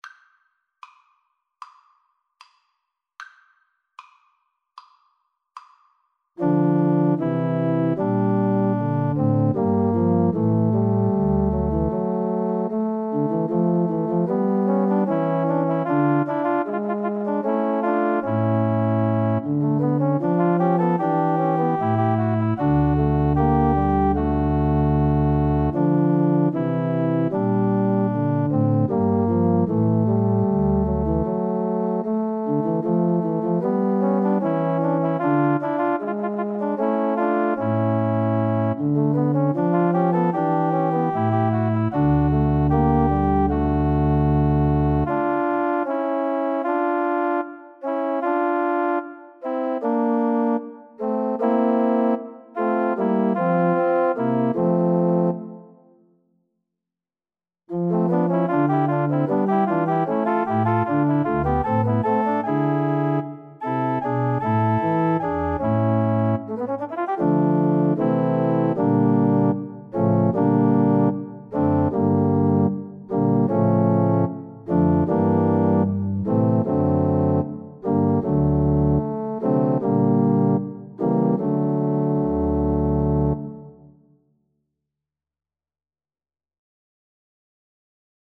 Cello
4/4 (View more 4/4 Music)
A minor (Sounding Pitch) (View more A minor Music for Cello )
Andante =c.76
Classical (View more Classical Cello Music)